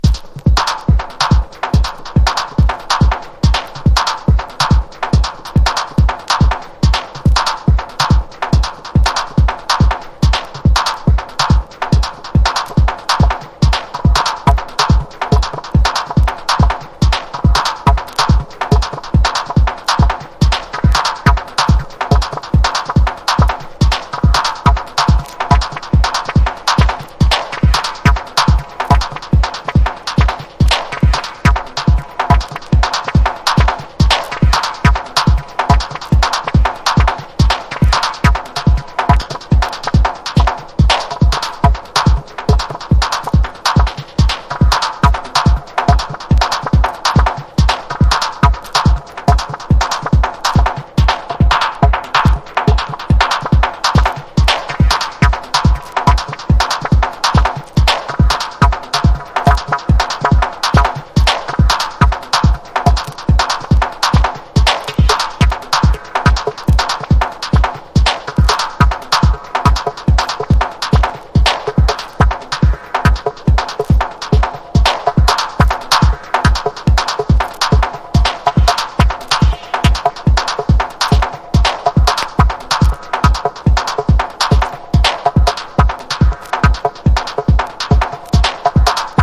クールなベースにディレイやリヴァーヴを多用したダブワイズでトラックを作り上げていくダブ・テクノ初期傑作！
CHICAGO# DUB / LEFTFIELD